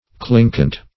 Meaning of clinkant. clinkant synonyms, pronunciation, spelling and more from Free Dictionary.
Clinkant \Clin"kant\ (kl[i^][ng]"kant), a.